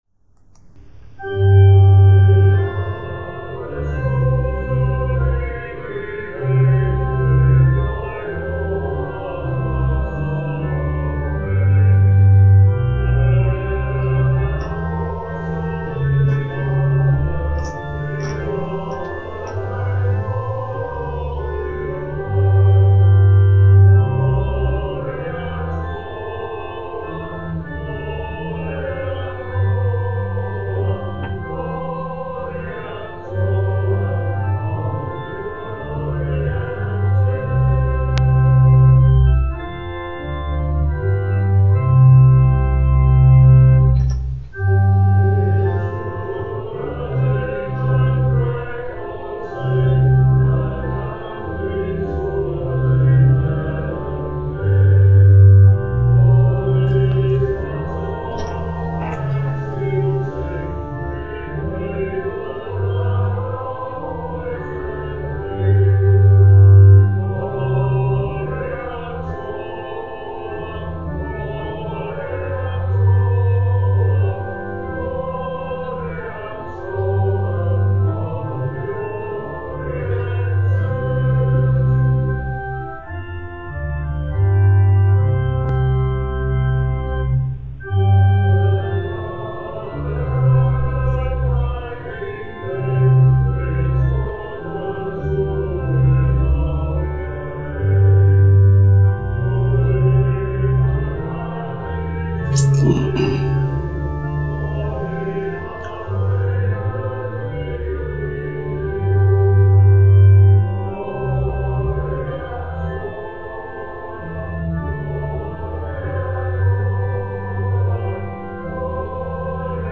Choral version
school_song_choral.mp3